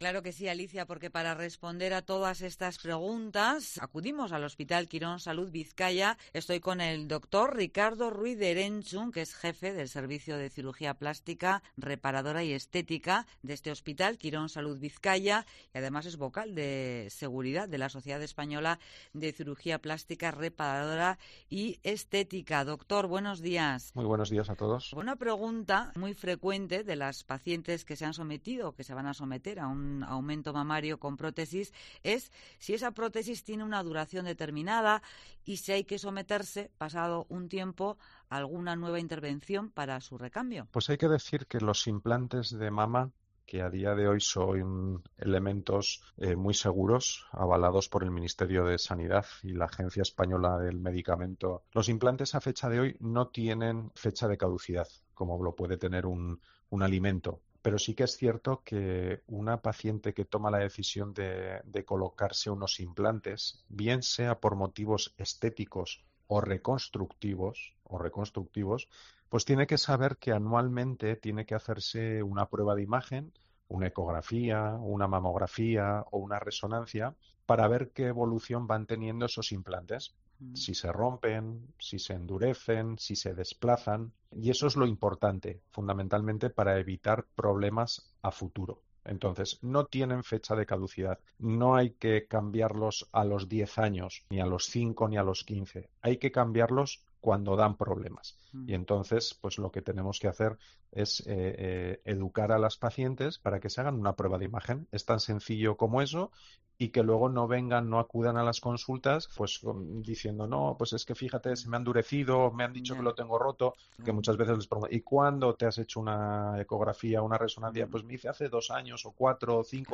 ha aclarado en esta interesante entrevista que aunque a día de hoy los implantes de mama